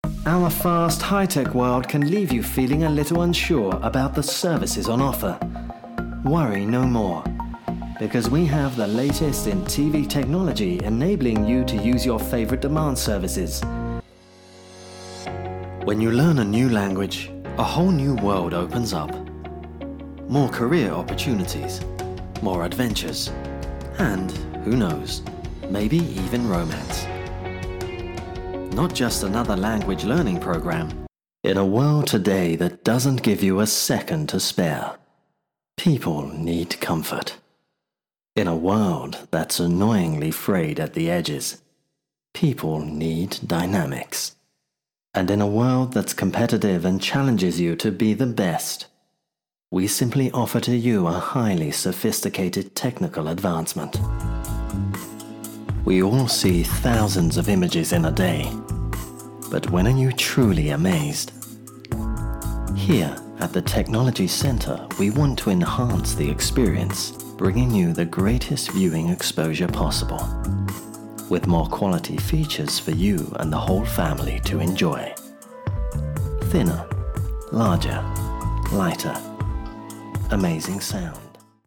dunkel, sonor, souverän, hell, fein, zart, sehr variabel
Mittel minus (25-45)
Eigene Sprecherkabine